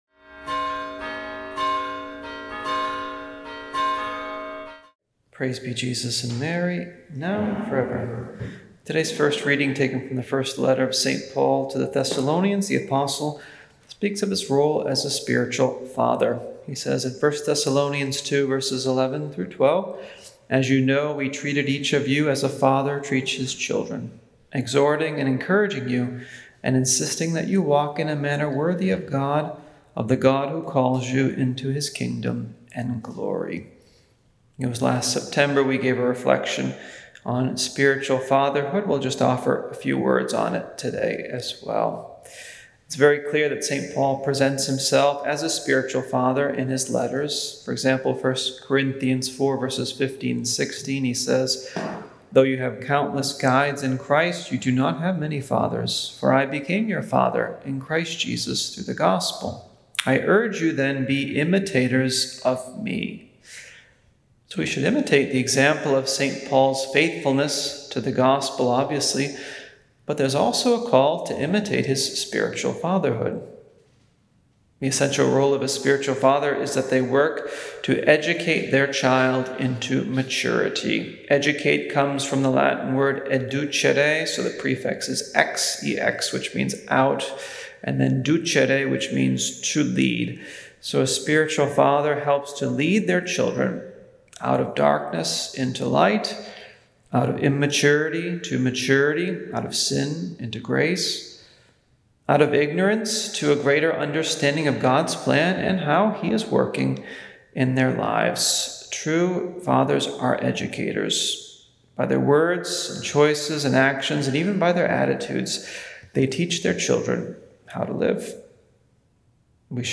Homily